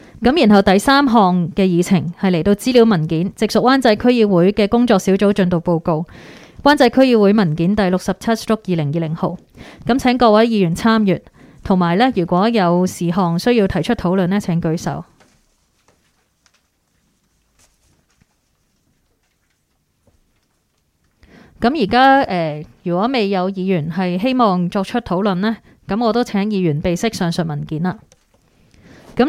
区议会大会的录音记录
湾仔民政事务处区议会会议室